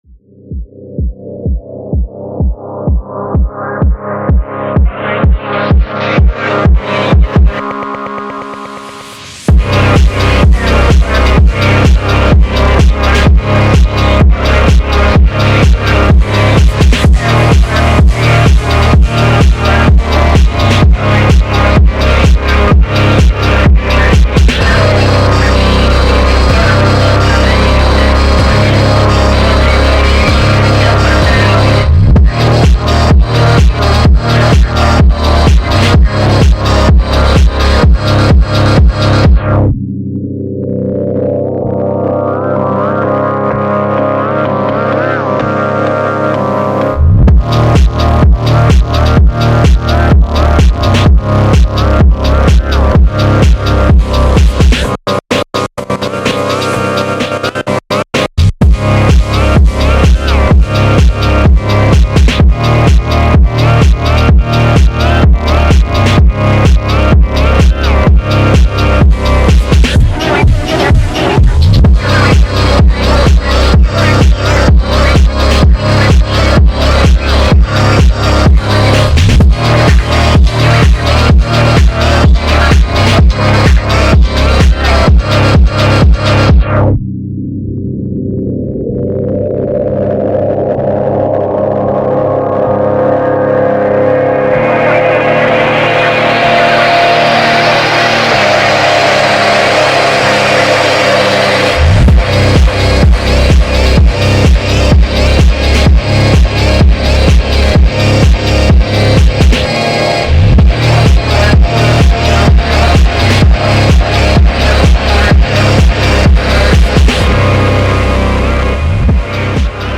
ﻓﺮﻣﺖ MP3 رﻳﻤﻴﻜﺲ ﻓﻮﻧﮏ ﺑﻰ ﻛﻠﺎم ﻣﻰ ﺑﺎﺷﺪ و ﻣﺘﻦ ﻧﺪارد